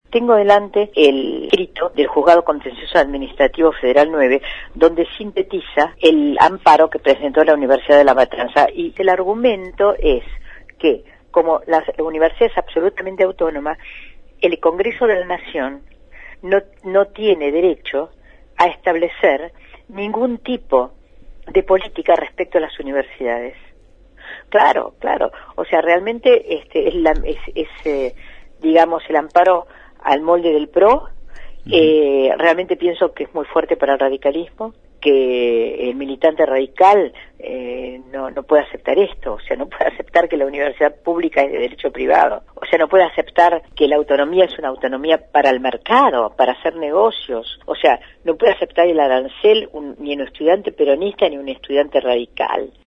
En el marco de una masiva convocatoria a la Marcha Nacional en defensa de la Educación y la Universidad Pública, conversamos con Adriana Puiggrós sobre el proyecto de Ley de Implementación efectiva de la responsabilidad del Estado en el Nivel de Educación Superior, aprobado el 28 de octubre de 2015 en el Senado de la Nación, por unanimidad en general y por 40 votos a favor y 12 en contra. Se trata de una modificatoria a la Ley de Educación Superior Nro 24521 impulsada por la Licenciada Puiggrós, entonces Diputada Nacional por la Provincia de Buenos Aires del Partido Frente Grande, y la Univerisdad Nacional de La Plata.